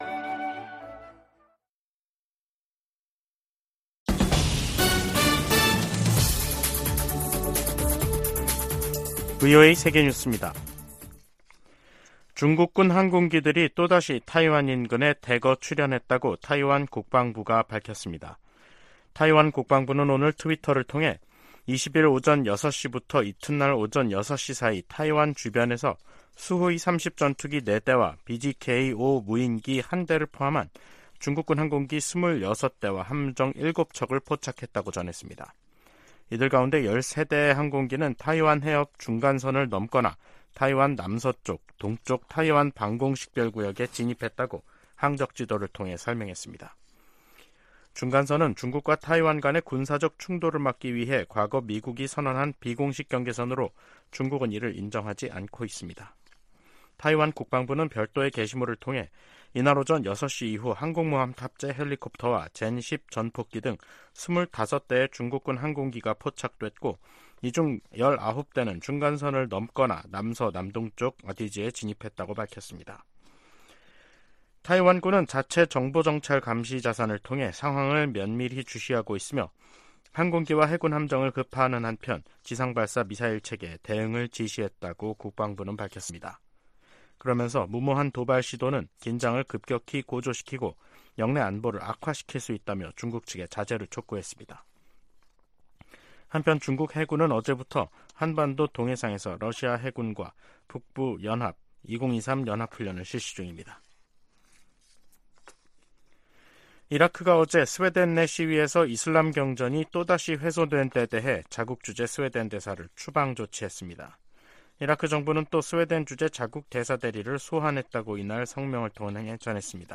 VOA 한국어 간판 뉴스 프로그램 '뉴스 투데이', 2023년 7월 21일 2부 방송입니다. 백악관은 월북 미군 병사의 안전과 소재 파악에 최선을 다하고 있지만 현재 발표할 만한 정보는 없다고 밝혔습니다. 미국과 한국의 핵협의그룹(NCG)을 외교・국방 장관 참여 회의체로 격상하는 방안이 미 상원에서 추진되고 있습니다. 미 국방부가 전략핵잠수함(SSBN)의 한국 기항을 비난하며 핵무기 사용 가능성을 언급한 북한의 위협을 일축했습니다.